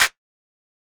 Perc (13).wav